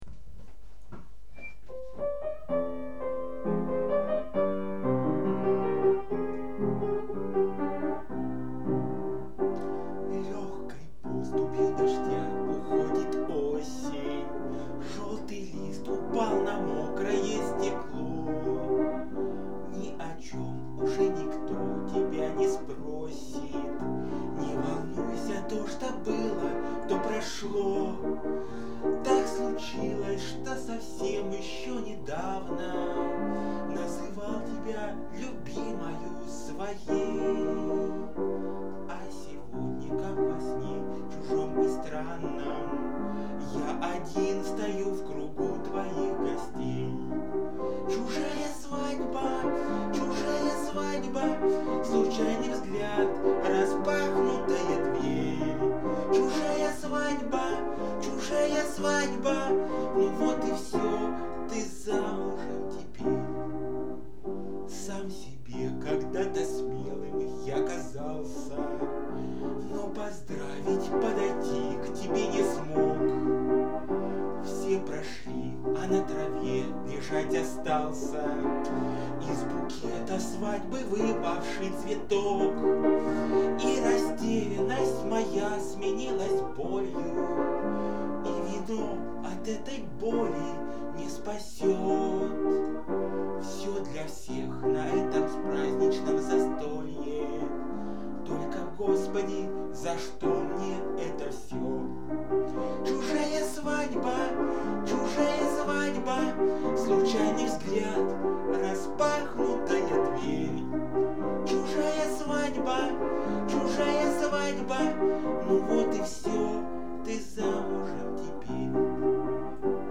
поет песню